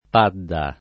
padda [ p # dda ]